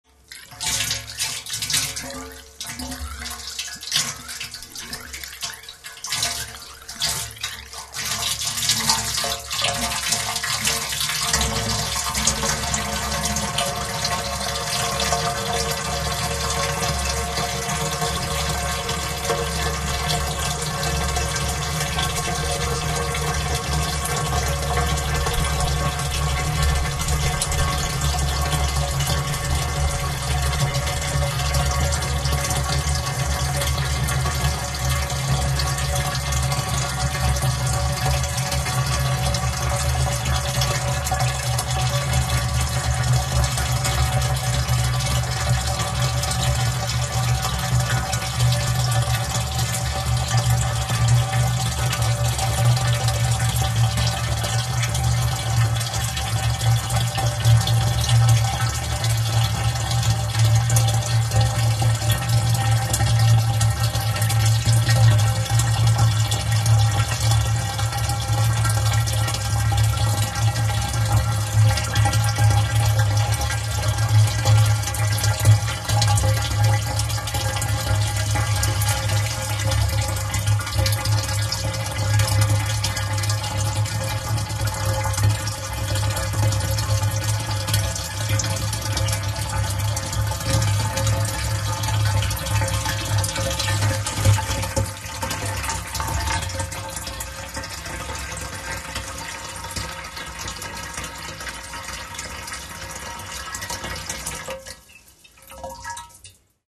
Звуки канистры
Звук обливания бензином из канистры во все стороны